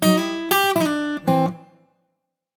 notification_005.ogg